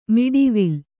♪ miḍivil